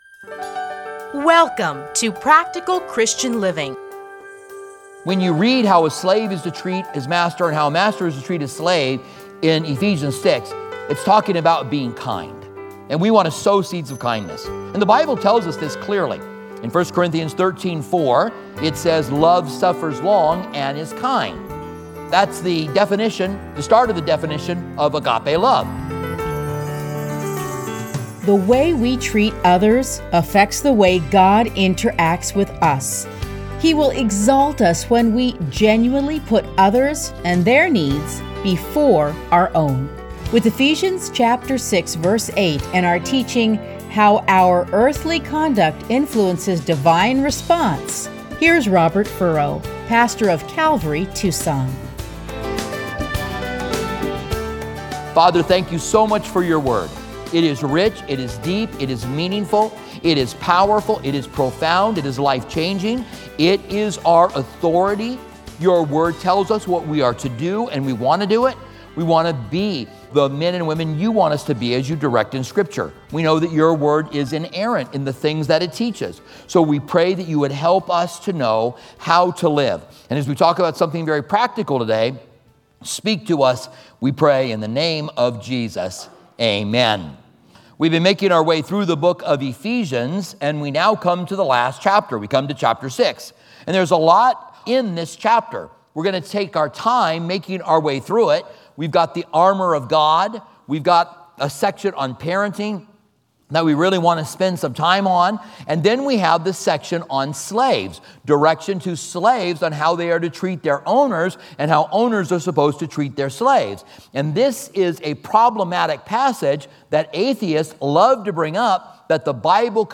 Listen to a teaching from Ephesians 6:8.